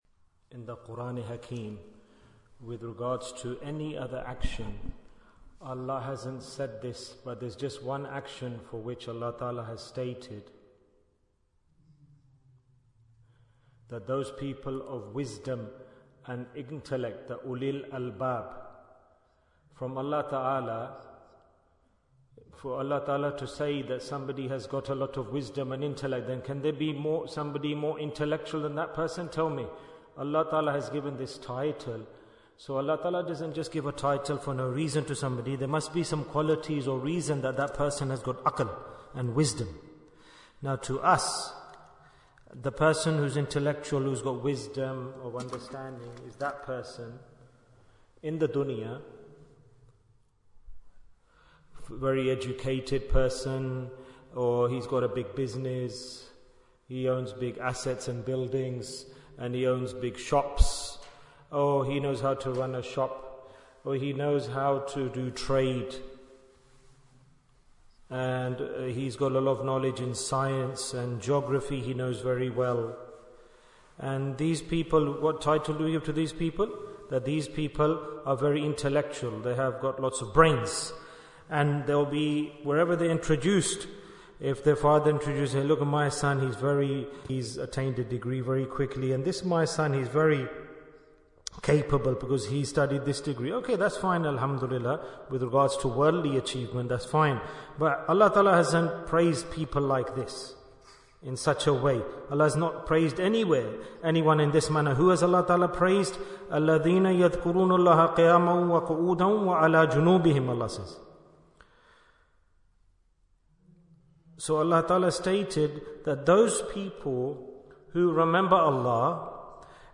Bayan, 24 minutes14th December, 2024